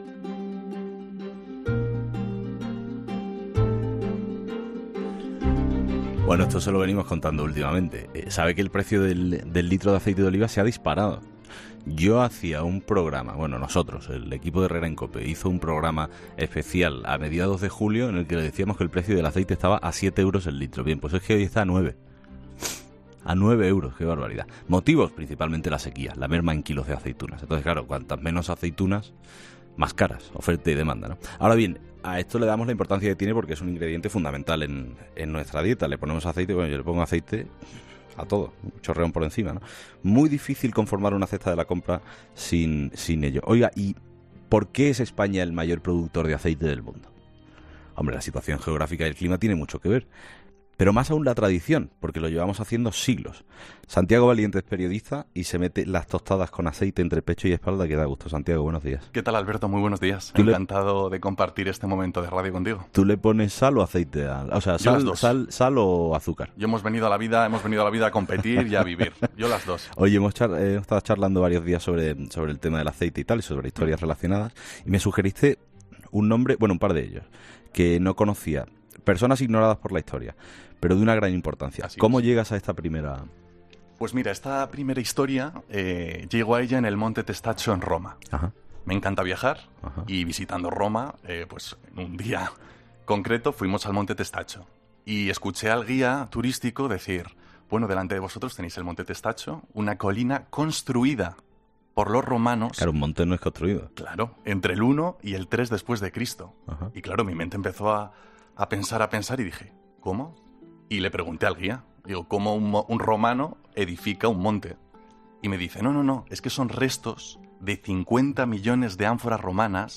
programa de radio